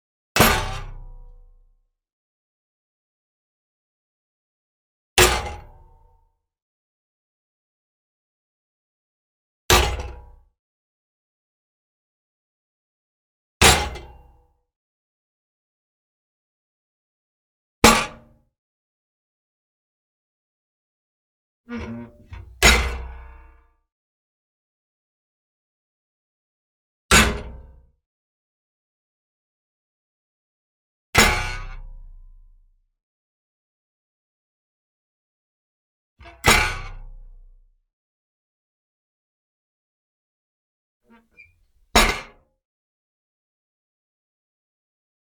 household
Heavy Iron Woodstove Door Slam Close